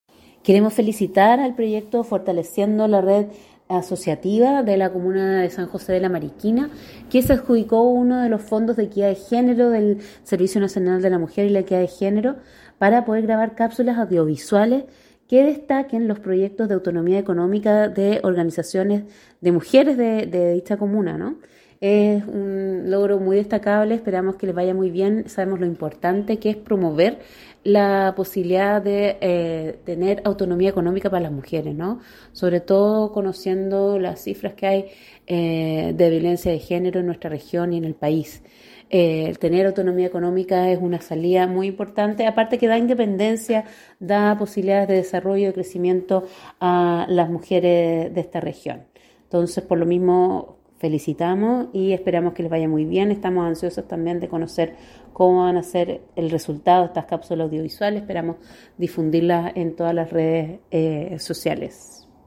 CUNA-FEG2022-SEREMI-MMEG-LOS-RIOS-FRANCISCA-CORBALAN-HERRERA.mp3